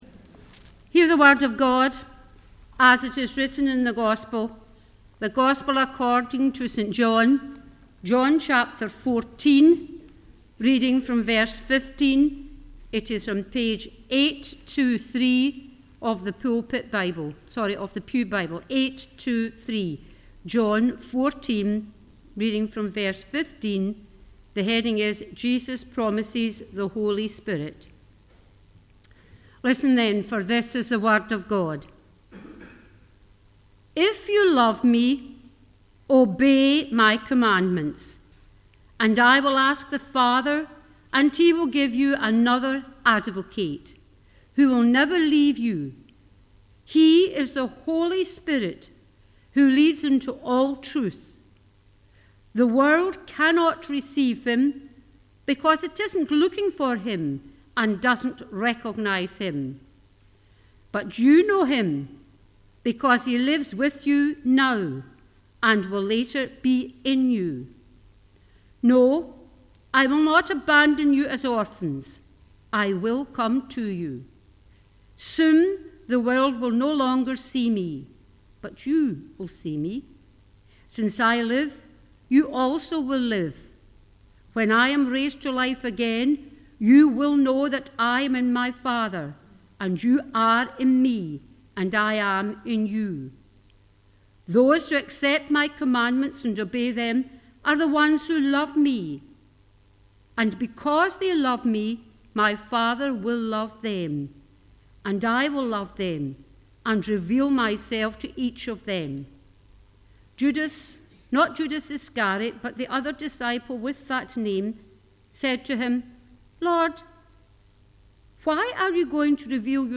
The Scripture Readings prior to the Sermon is John 14: 15-31